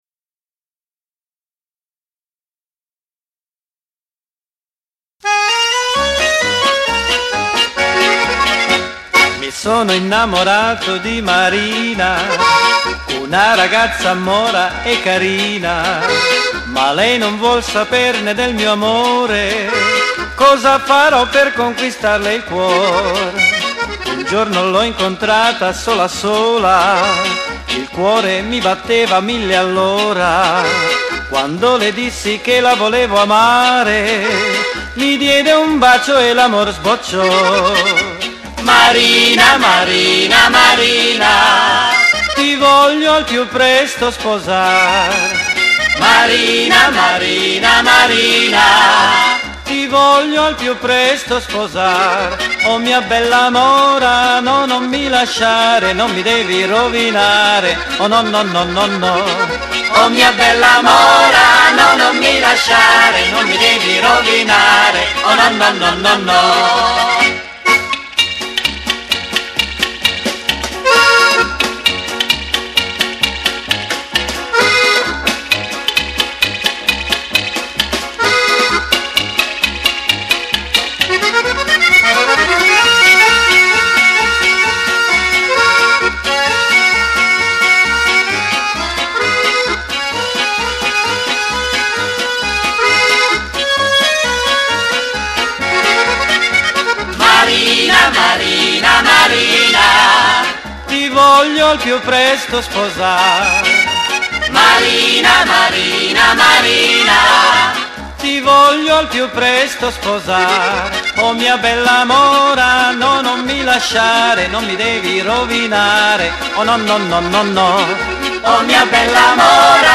RITMO ALLEGRO